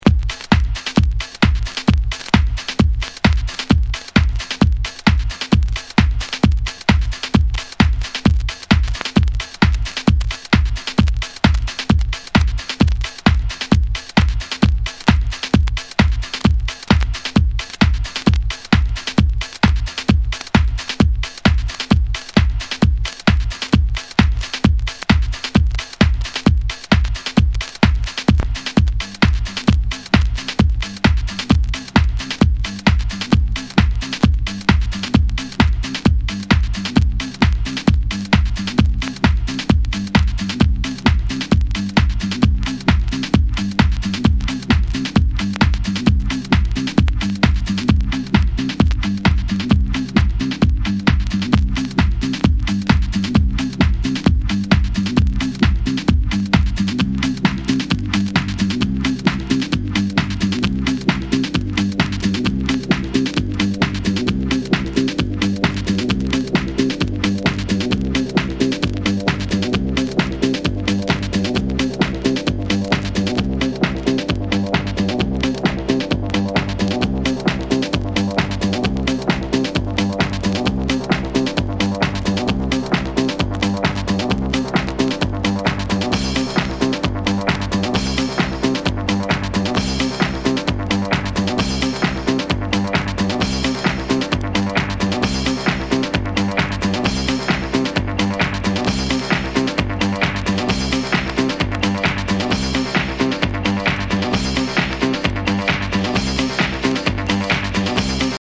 ハウス
キャッチーなメロディーで楽しい